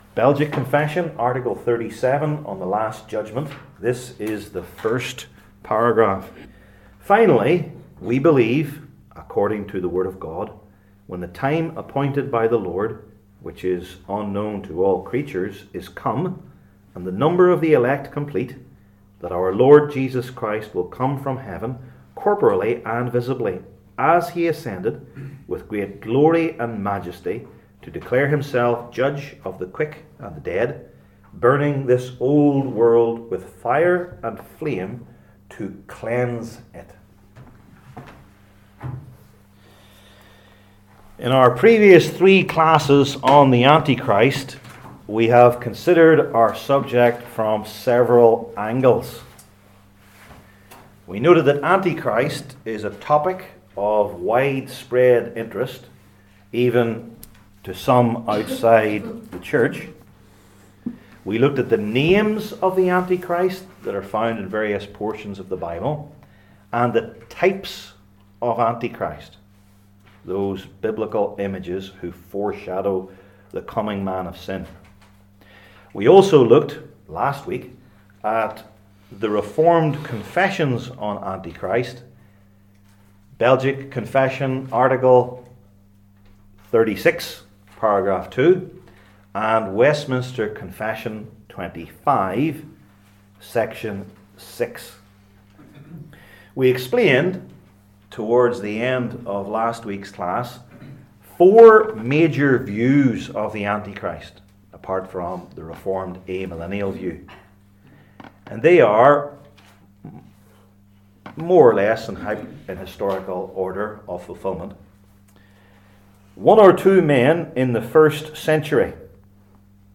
Passage: Revelation 17 Service Type: Belgic Confession Classes